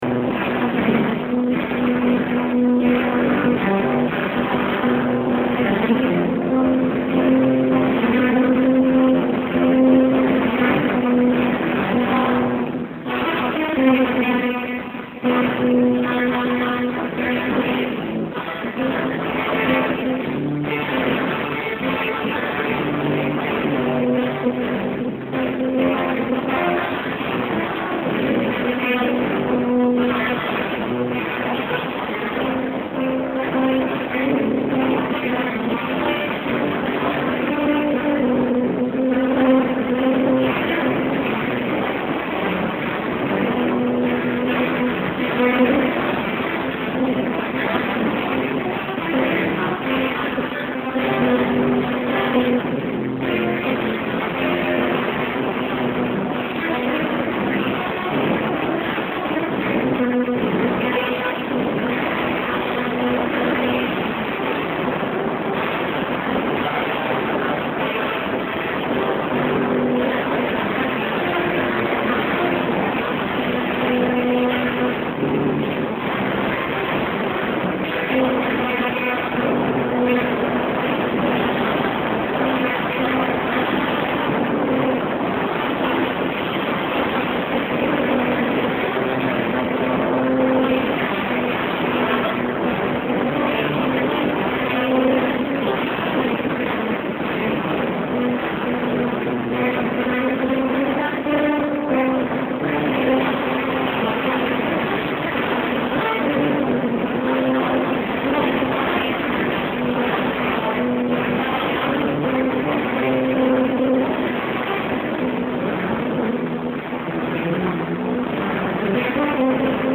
I called in from a Port Authority payphone to report on my take on the all-day seminars I attended. The sound was unlistenable, coming through another payphone that takes money, connects to the number you dialed, but is useless for making phone calls.
Loud music played right next to the payphone. I left the phone off the hook after I was done talking, hoping to get some quality audio of the band.
It sounds muffled and strange.
The weird thing is that for a few seconds near the end it actually sounds nearly decent.